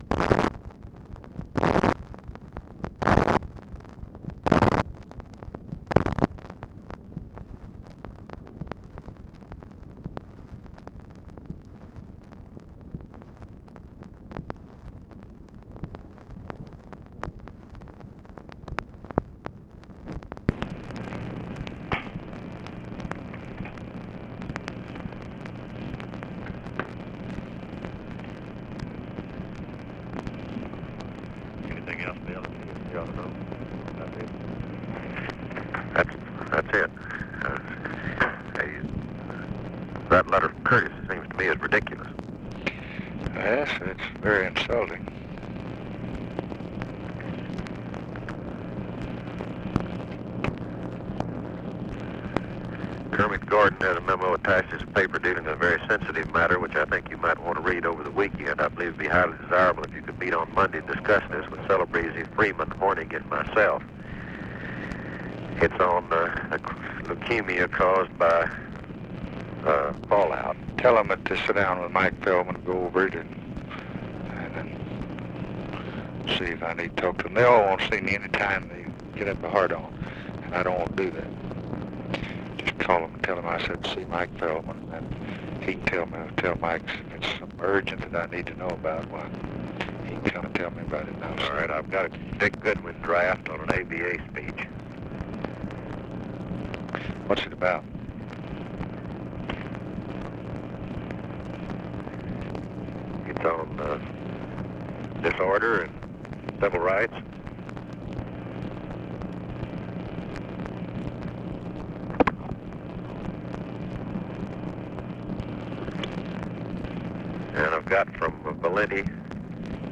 Conversation with WALTER JENKINS and OFFICE CONVERSATION, August 8, 1964
Secret White House Tapes